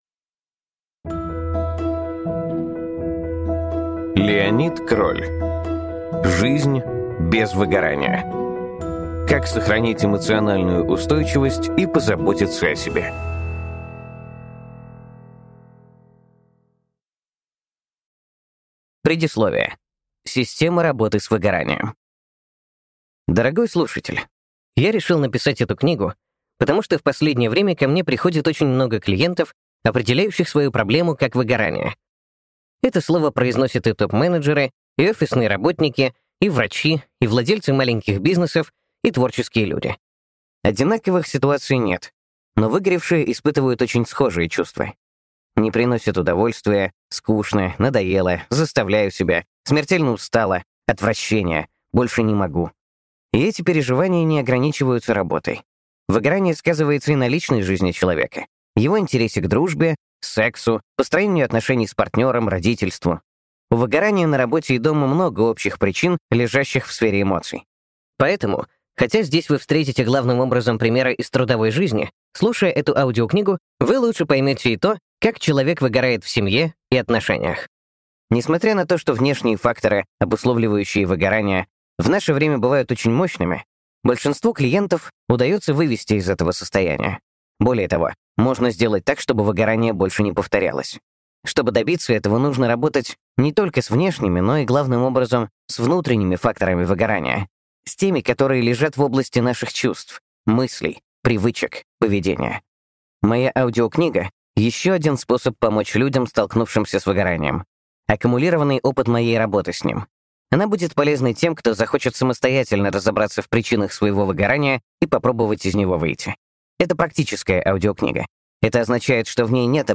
Аудиокнига Жизнь без выгорания: Как сохранить эмоциональную устойчивость и позаботиться о себе | Библиотека аудиокниг